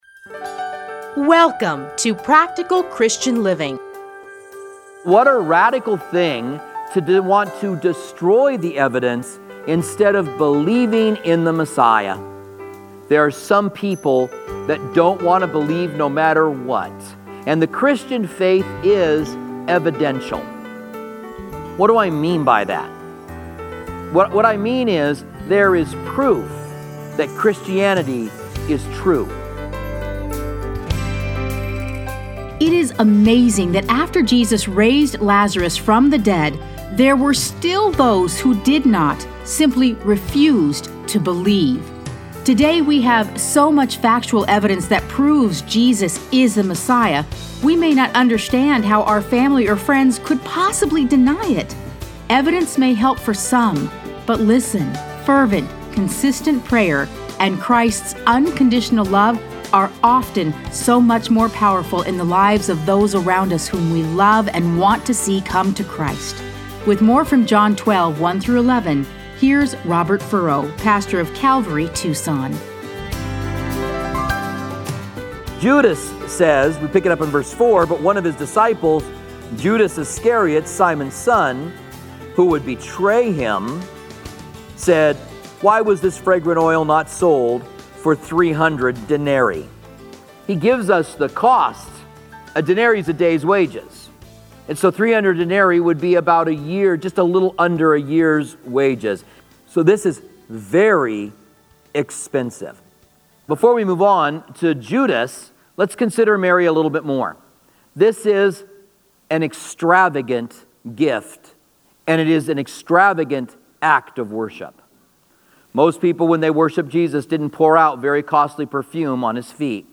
Listen to a teaching from John 12:1-12.